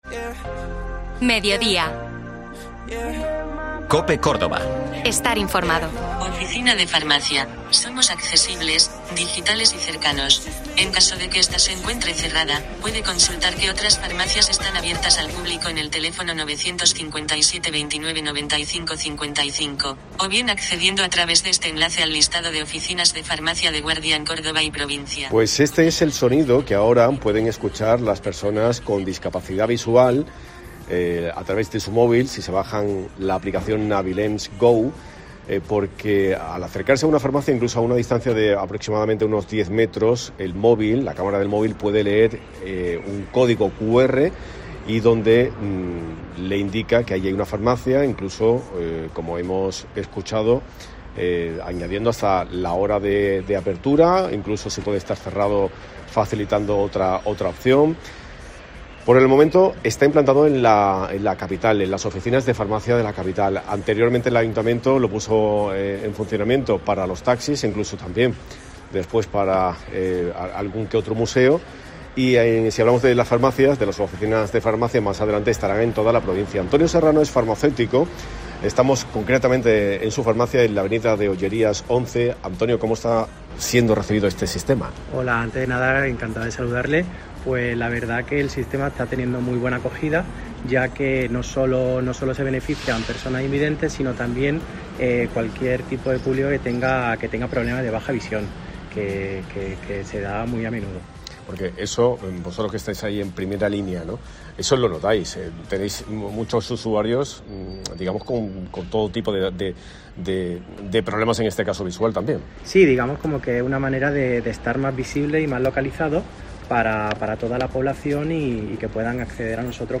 nos explica desde su farmacia cómo funcionan los códigos Navilens